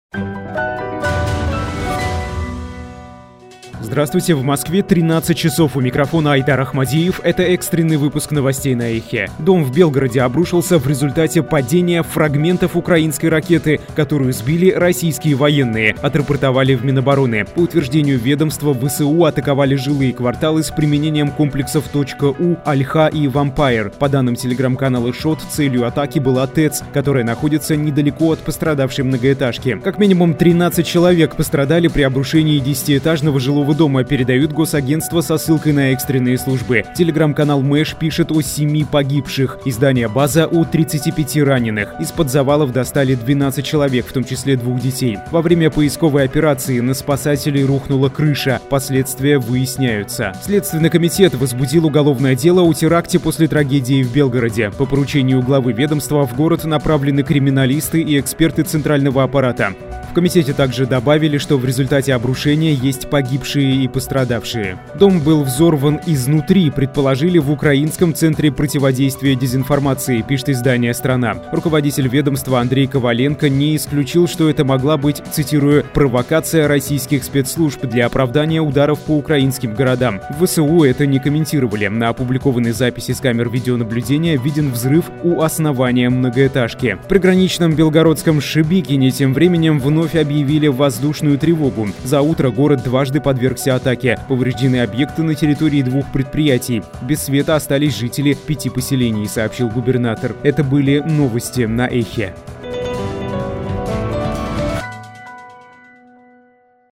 Экстренный выпуск новостей